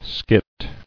[skit]